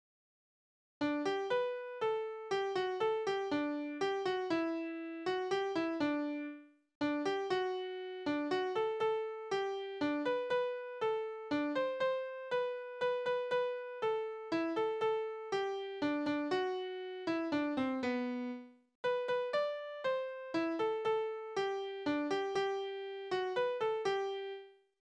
Heimatlieder: Sehnsucht nach der Heimat
Tonart: G-Dur
Taktart: 3/4
Tonumfang: kleine Dezime
Besetzung: vokal